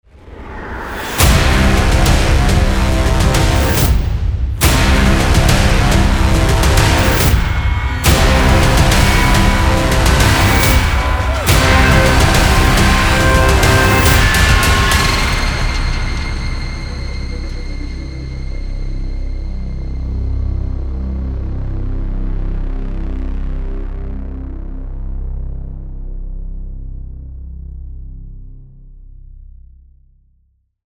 Dark-Epic-Cyber-Trailer-short-LDS-Disciple.mp3